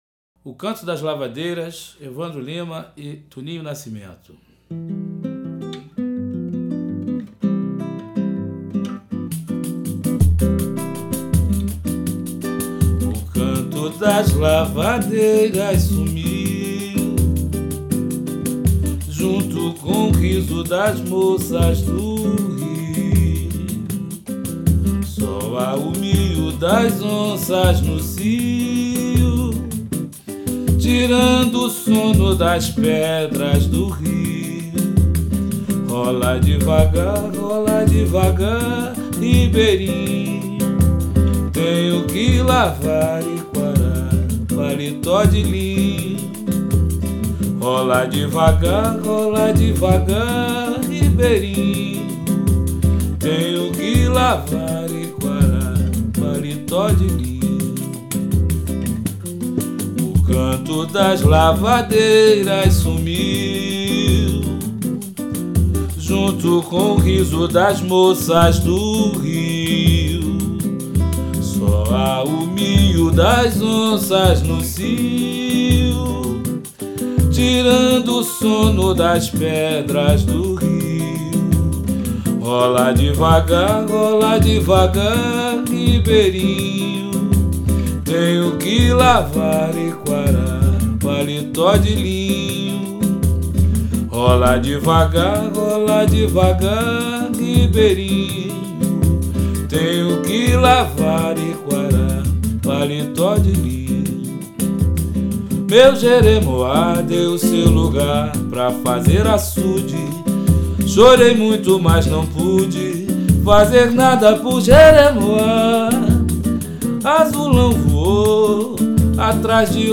samba chula Samba Dolente canto de trabalho